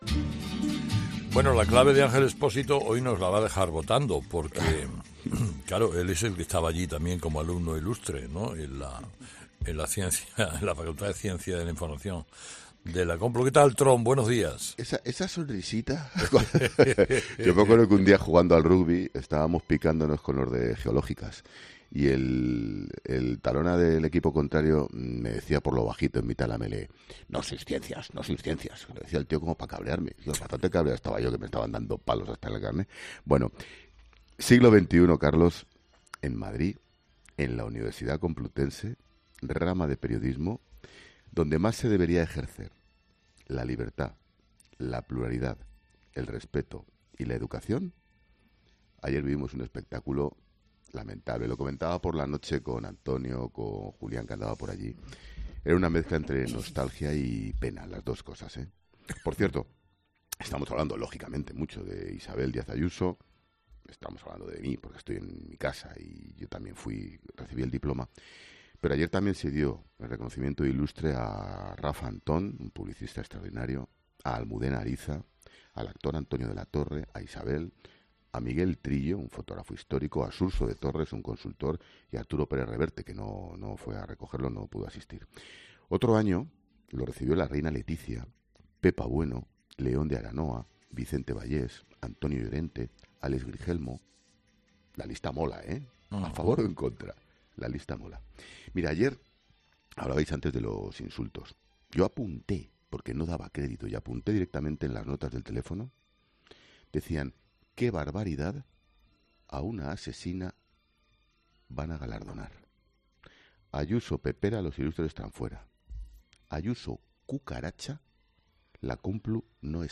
Ángel Expósito habla de Isabel Díaz Ayuso tras sufrir el escrache en la Universidad Complutense de Madrid